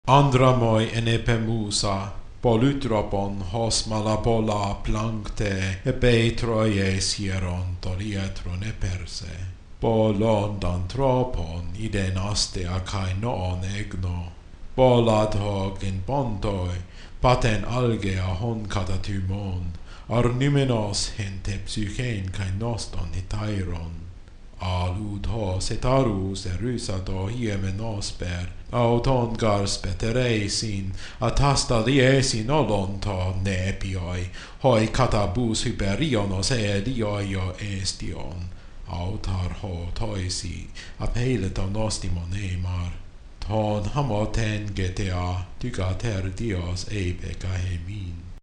MP3 of the proem to the Odyssey to get you started.